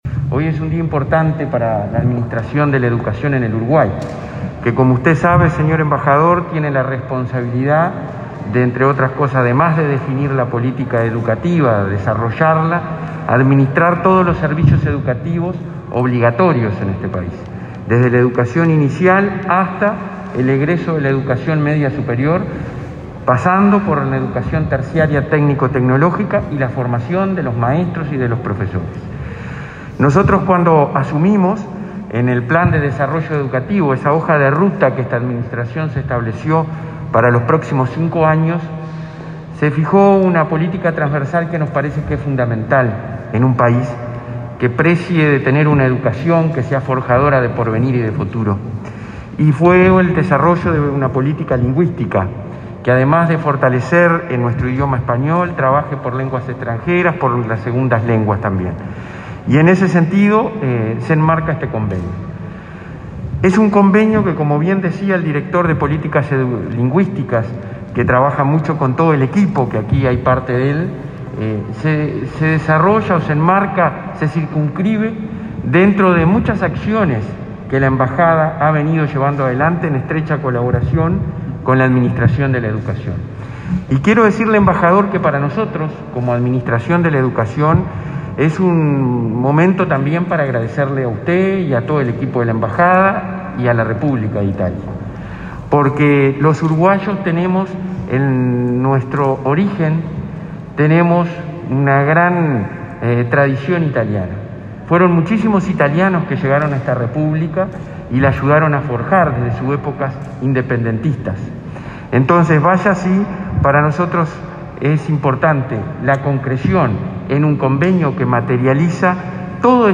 Palabras del presidente del Codicen, Robert Silva, y el embajador italiano, Giovanni Iannuzzi
El presidente del Codicen, Robert Silva, y el embajador italiano en Uruguay, Giovanni Iannuzzi, participaron de la firma de un convenio de cooperación